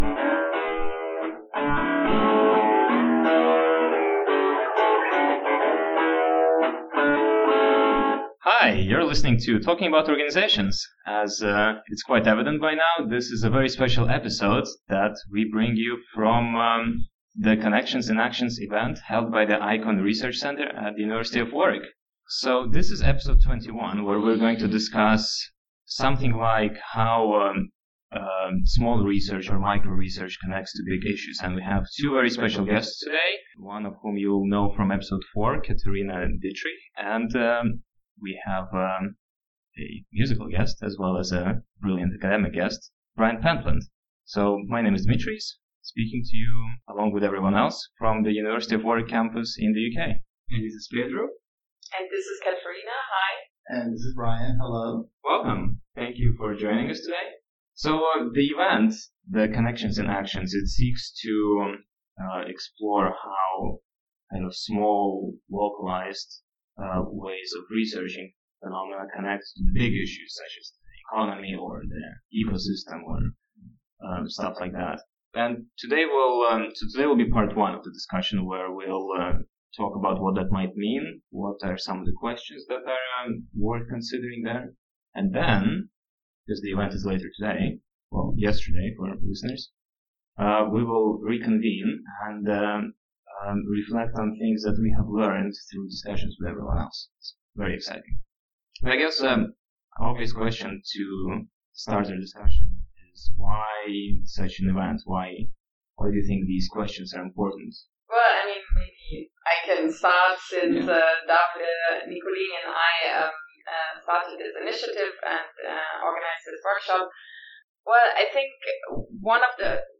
Special episode from a workshop on the utilization of existing and new ‘micro-sociological’ and relational approaches in organization studies.
From the ‘Connections in Action’ workshop held by the IKON Research Unit at the University of Warwick, 5-6 December 2016